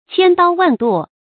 千刀萬剁 注音： ㄑㄧㄢ ㄉㄠ ㄨㄢˋ ㄉㄨㄛˋ 讀音讀法： 意思解釋： 本指古代凌遲的酷刑，后亦常用為咒罵語。